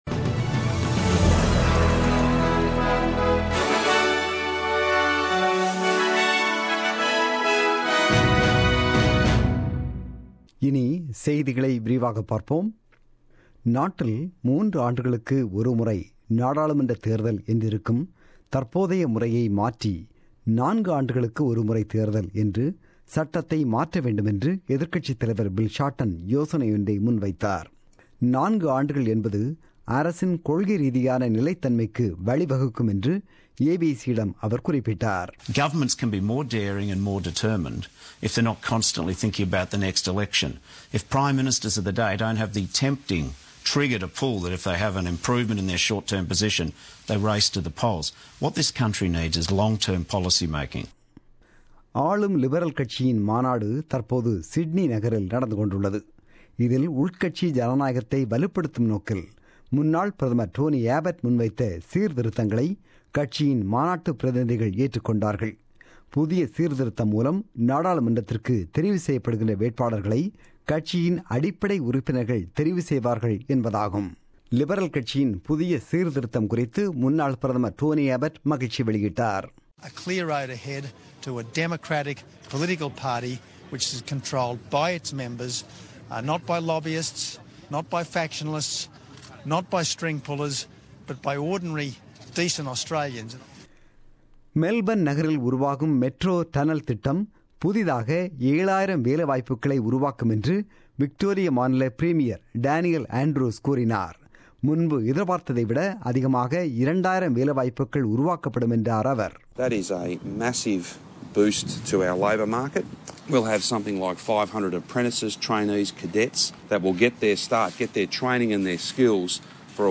The news bulletin broadcasted on 23 July 2017 at 8pm.